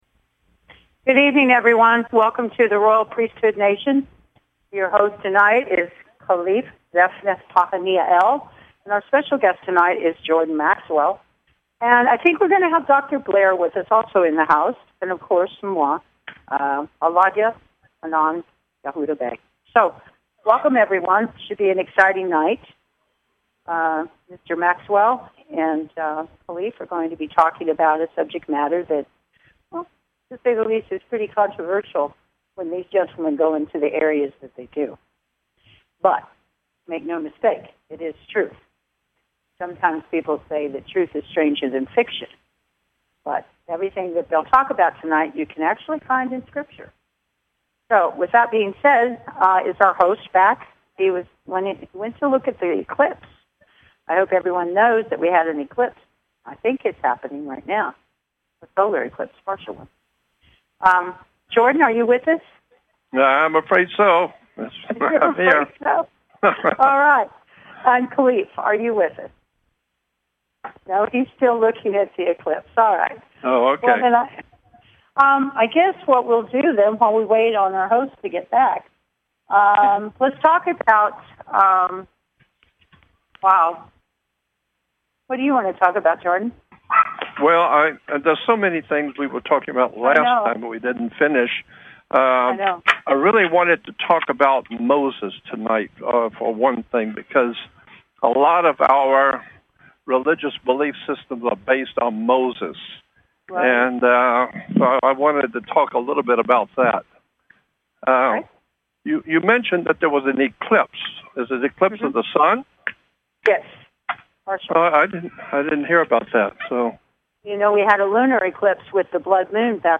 Guest, Jordan Maxwell
Interview with Jordan Maxwell